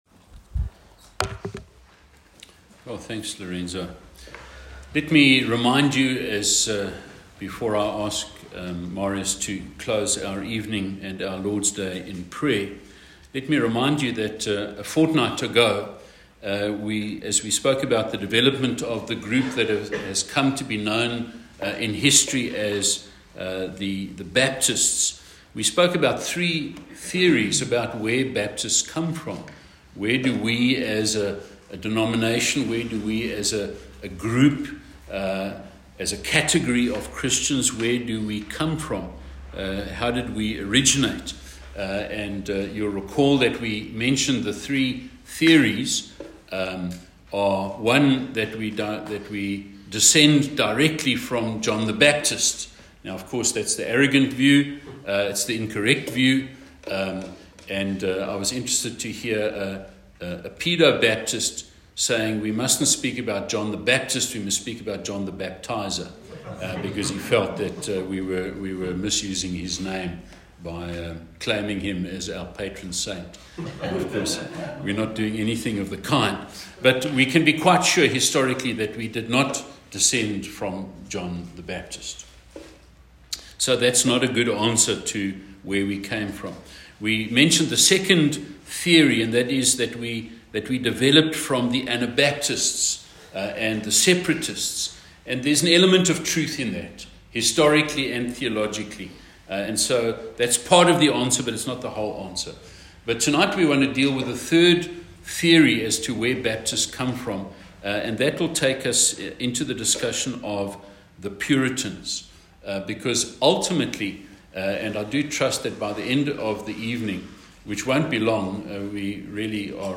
A message from the series "Church History."